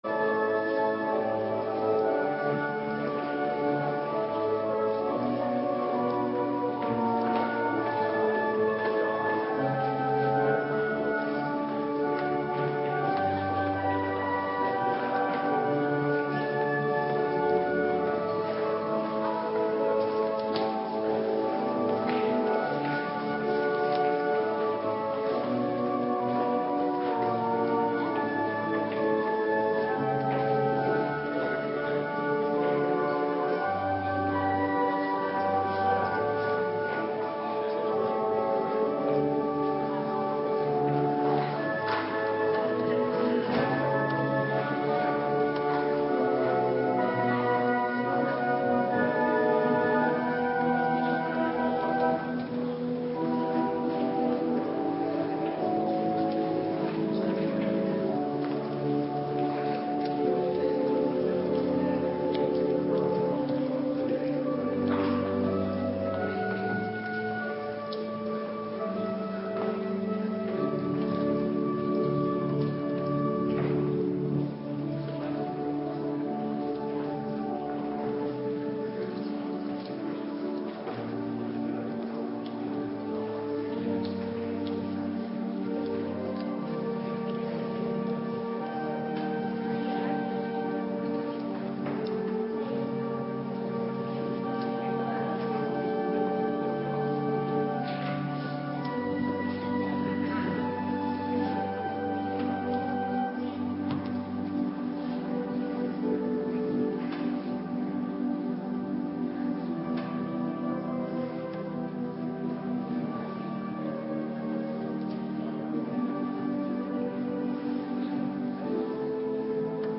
Morgendienst - Cluster 3
Locatie: Hervormde Gemeente Waarder